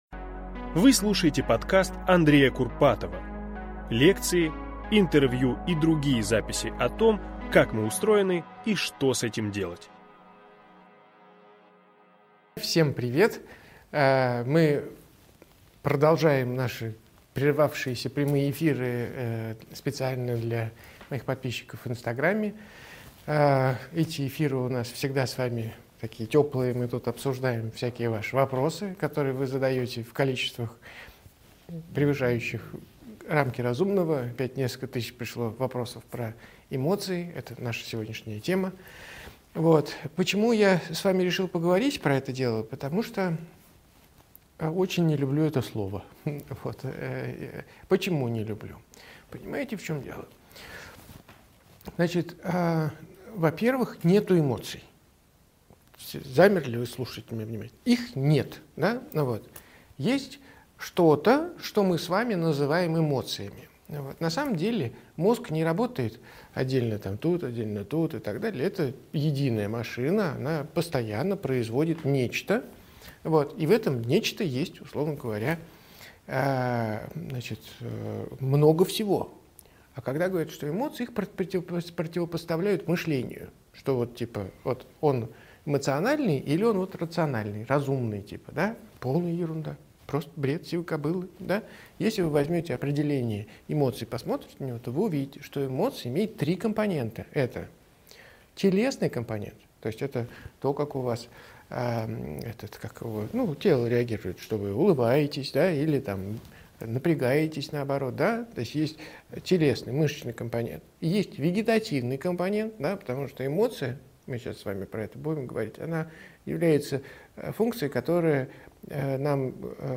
Аудиокнига Как понять себя и свои эмоции?